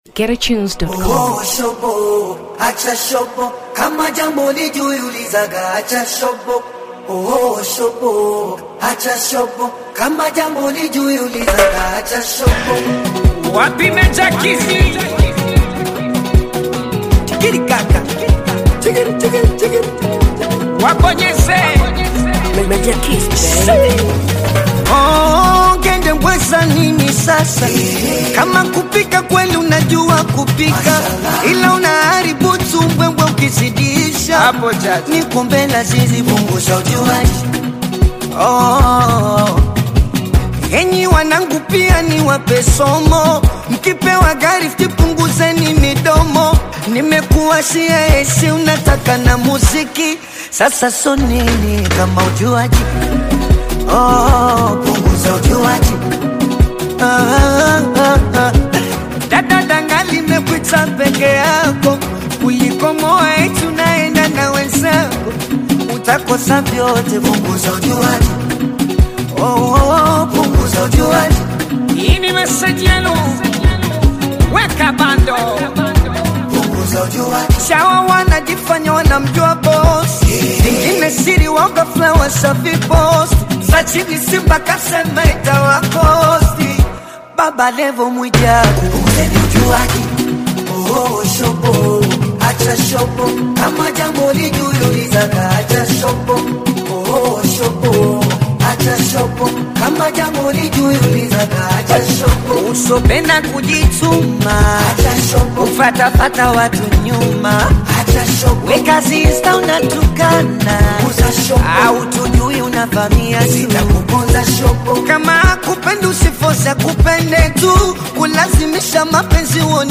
Afrobeats 2023 Tanzania